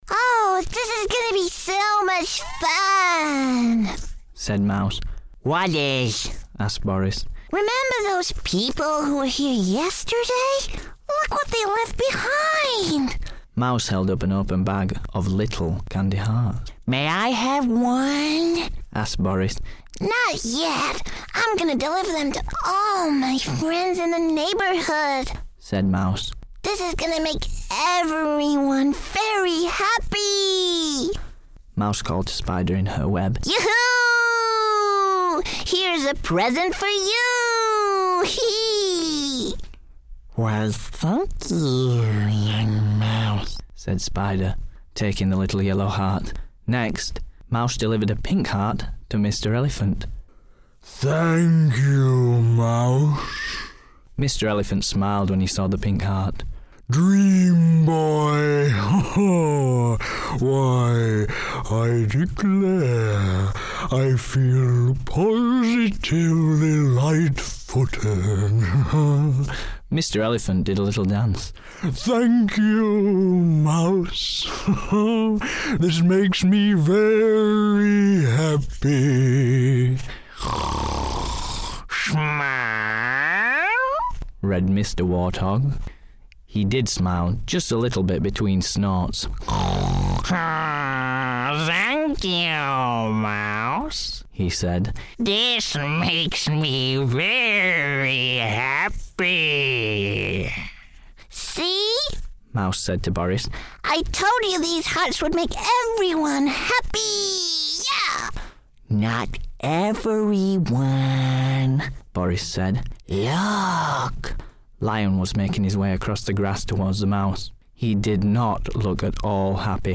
Animation reel
My animation voice real with a few characters I created - I have many more :) please like share and enjoy :)